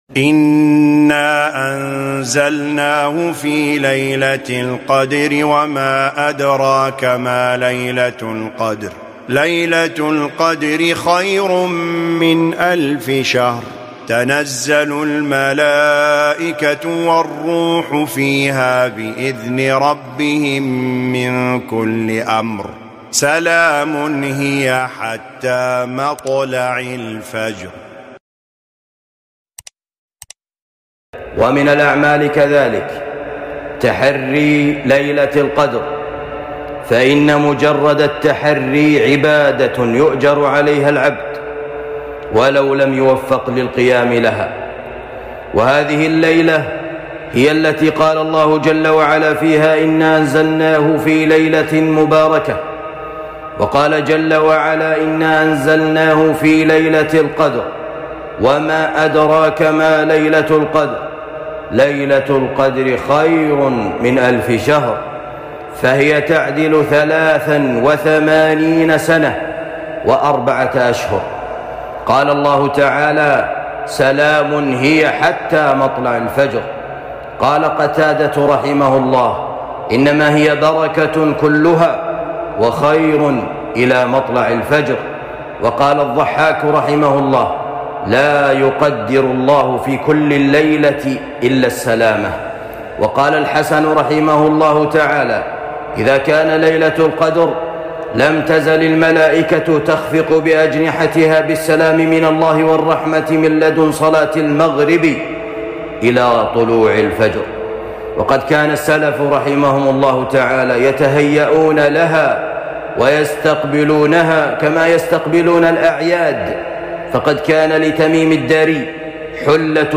موعظة عن ليلة القدر